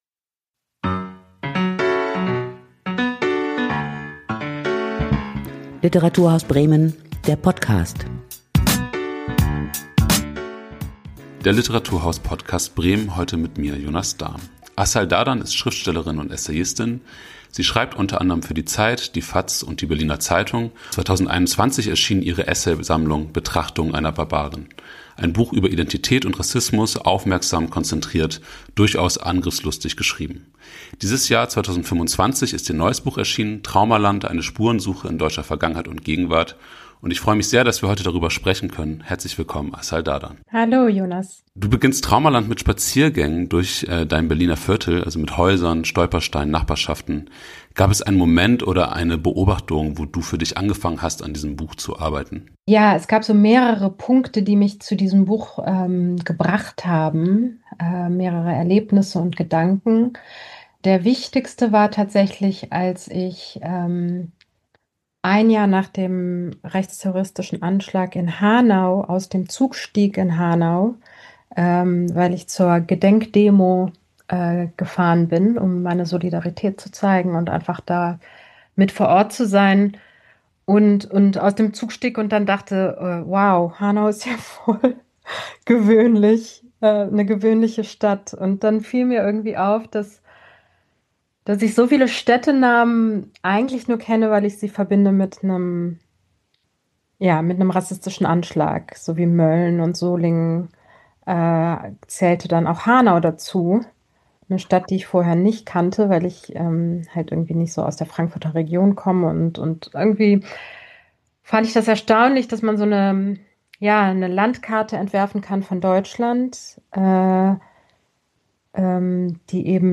Ein Gespräch über die Topografie des Erinnerns, verdrängte Geschichten und die Gegenwart des Gedenkens.